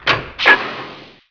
steambutton.wav